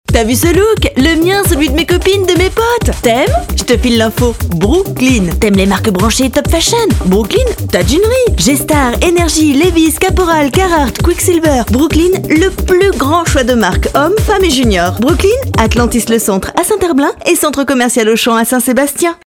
Pymprod réalise pour eux un spot publicitaire à leur image.